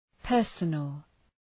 {‘pɜ:rsənəl}